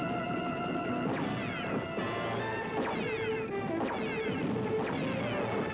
The futuristic guns of the Phantom Riders make the same sound as the guns used by the Colonial Warriors on the original Battlestar Galactica!
Phantom-Rider-gun.wav